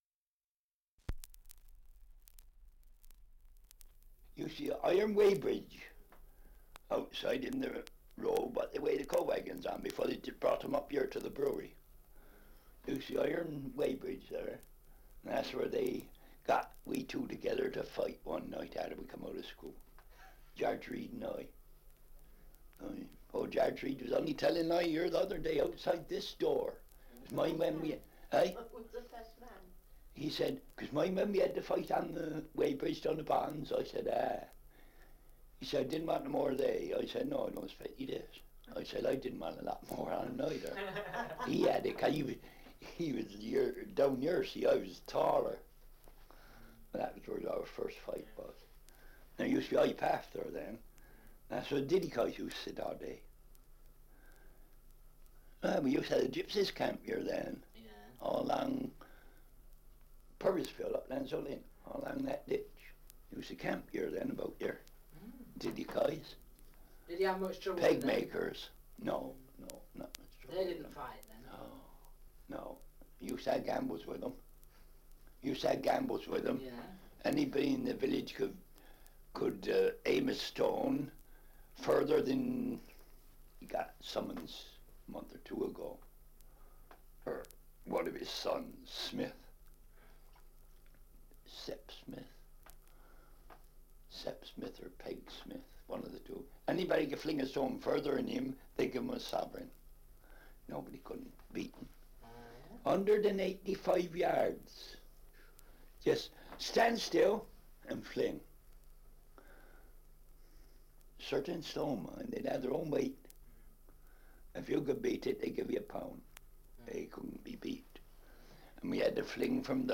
2 - Survey of English Dialects recording in Weston, Somerset
78 r.p.m., cellulose nitrate on aluminium